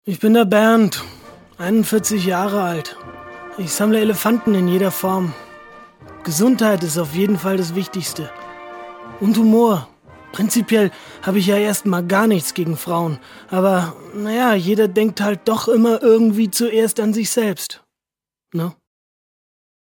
deutscher Sprecher und Schauspieler
schweizerdeutsch
Sprechprobe: Sonstiges (Muttersprache):